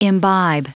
Prononciation du mot imbibe en anglais (fichier audio)
Prononciation du mot : imbibe